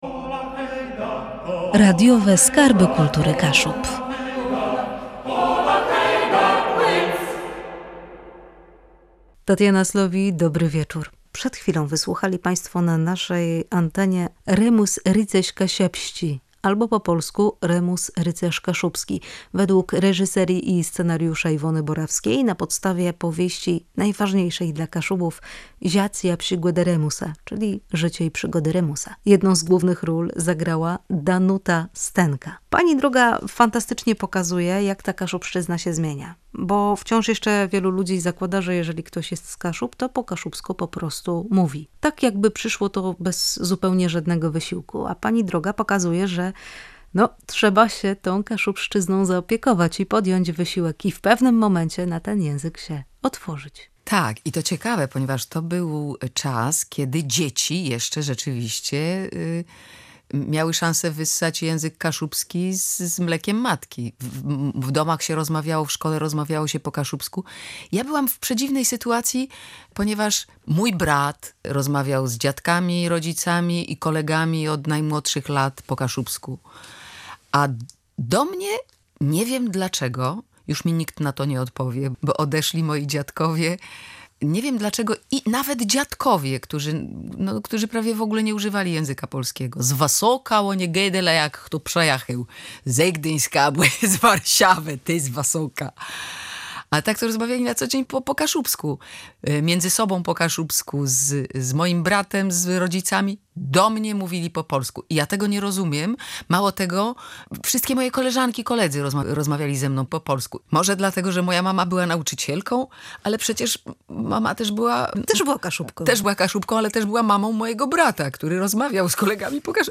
wywiad-z-Danuta-Stenka.mp3